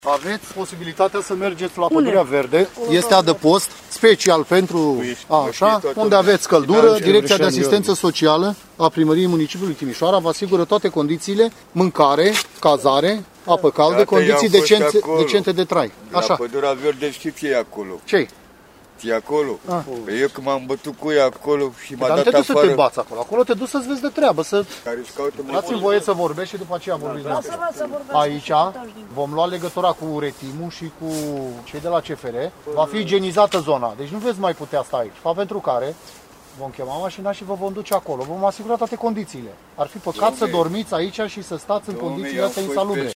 politist-local-evacuare-tabara.mp3